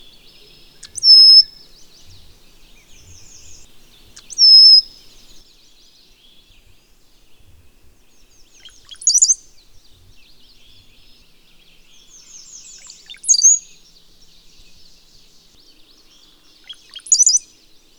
Brown-headed Cowbird
Molothrus ater